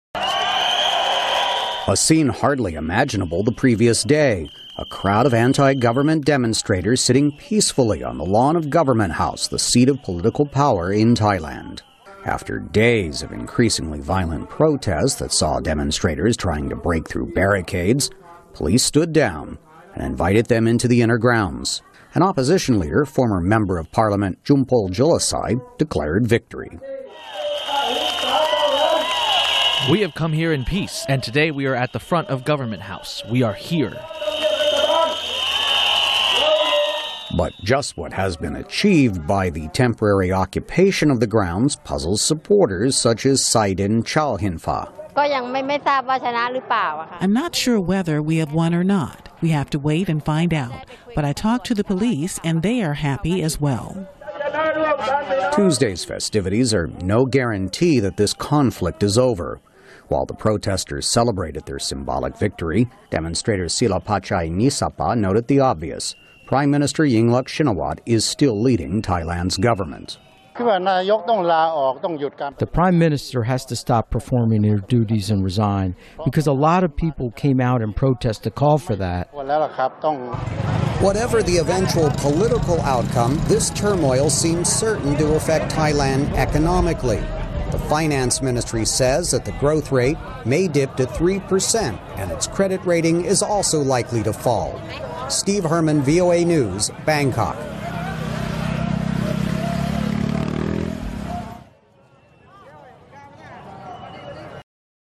VOA常速英语|泰国抗议者在国王生日前庆祝停战(VOA视频)